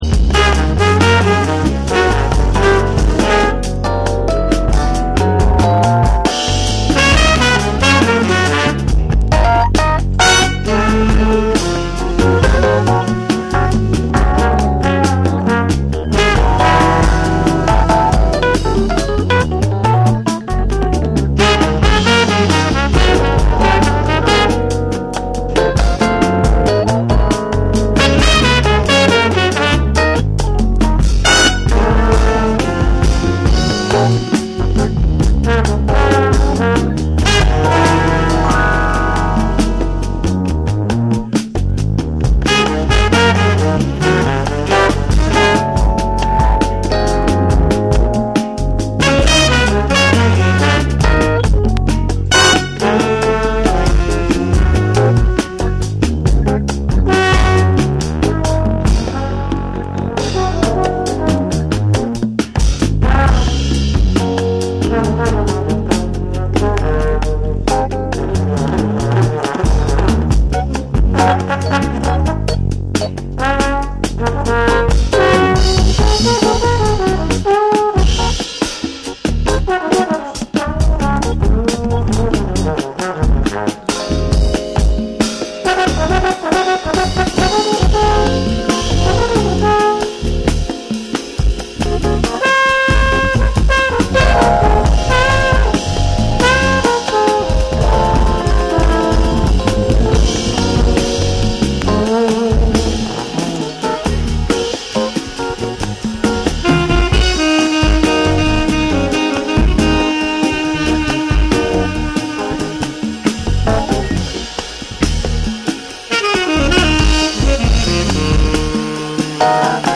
パーカッシヴなリズムとスピリチュアルなムードが交差するジャズ・ナンバー
SOUL & FUNK & JAZZ & etc / TECHNO & HOUSE / DETROIT